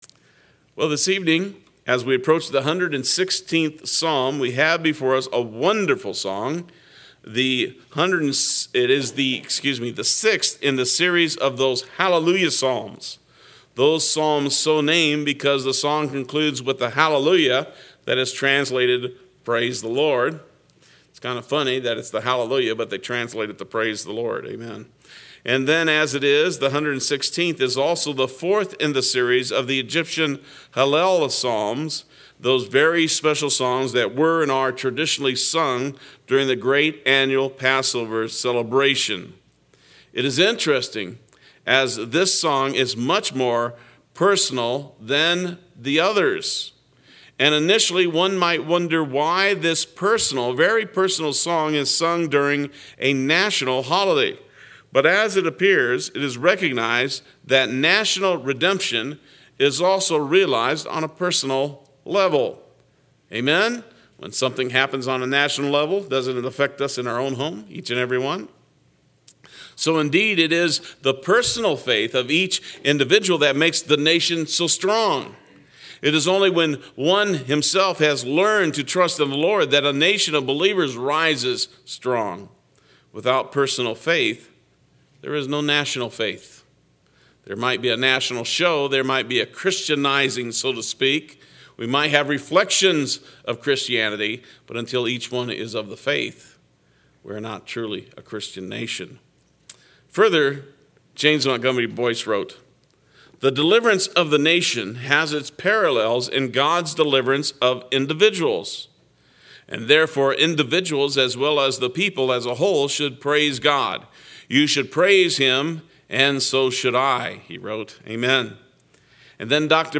Play Sermon Get HCF Teaching Automatically.
Psalm 116 Wednesday Worship